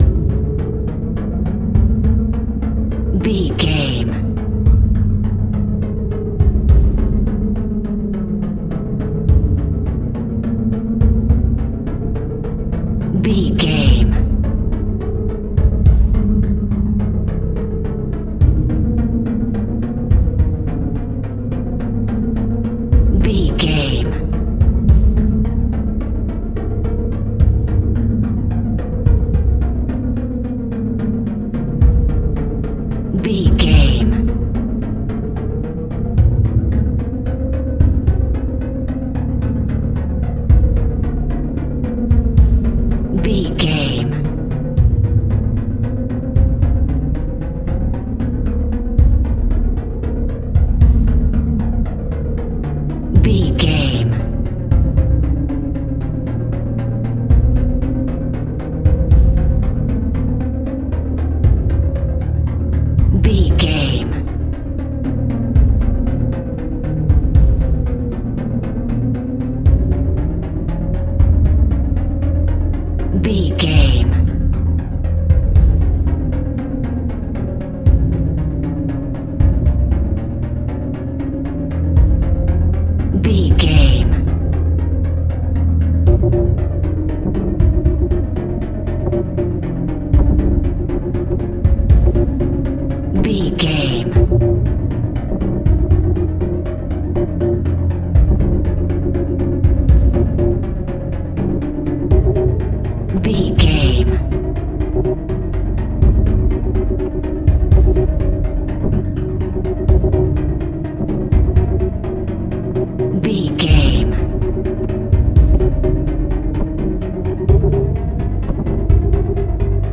Building Up Tension.
In-crescendo
Thriller
Aeolian/Minor
ominous
dark
suspense
haunting
eerie
strings
synth
ambience
pads